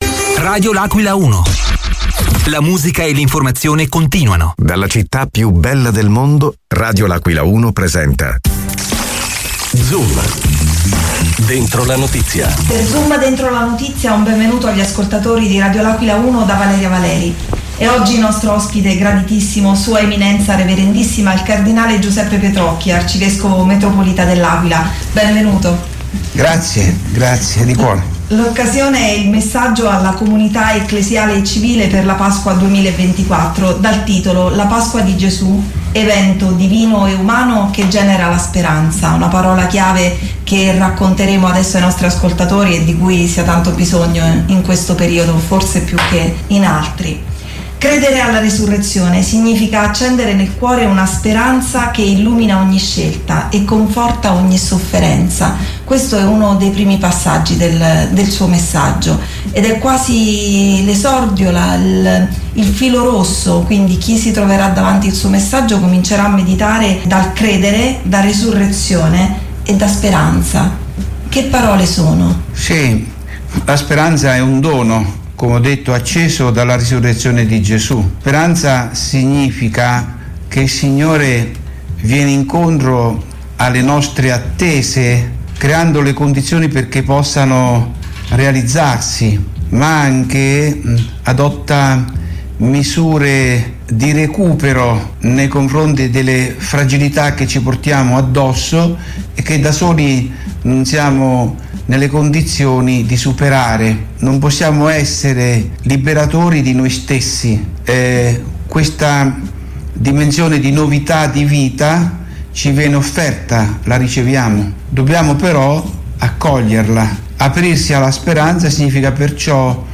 L’AQUILA – Come ogni anno l’Arcivescovo Metropolita dell’Aquila S. E. Cardinale Giuseppe Petrocchi anche questa volta è stato ospite negli studi di Radio L’Aquila 1 per parlare del messaggio rivolto alla comunità ecclesiale e civile.